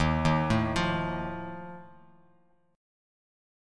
D#sus2b5 chord